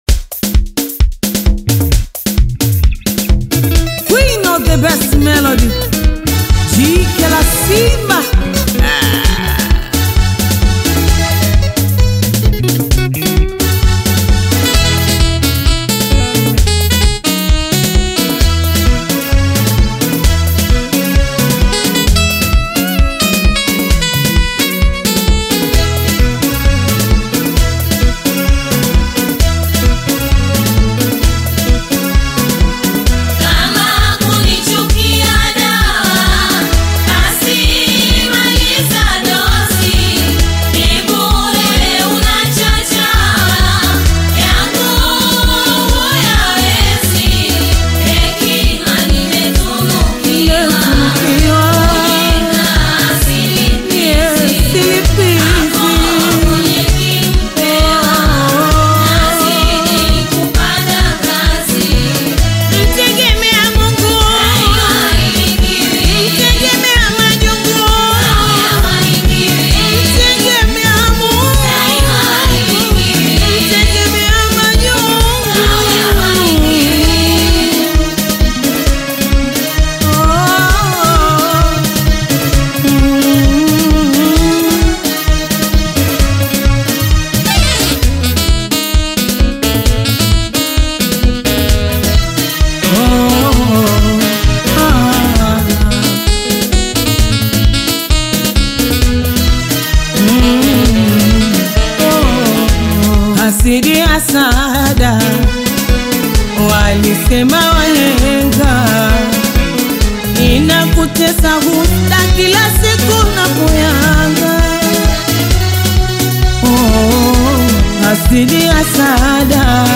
Taarabu